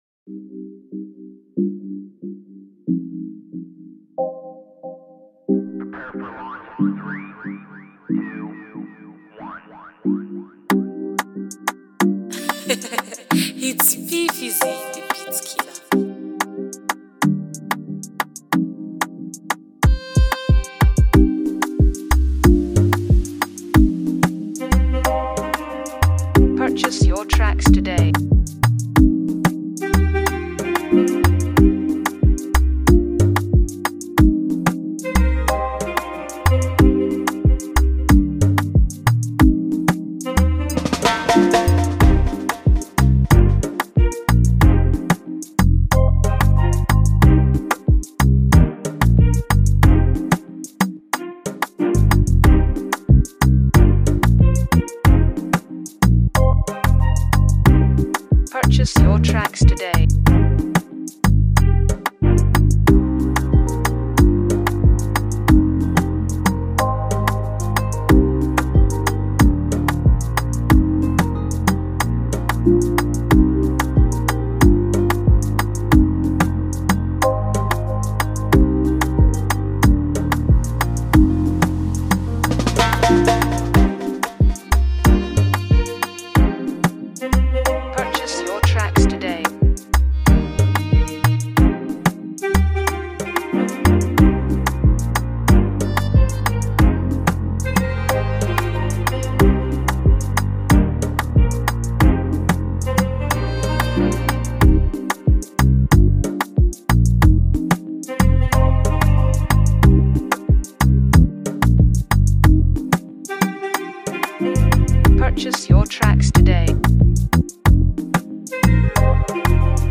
the latest Afrobeat instrumental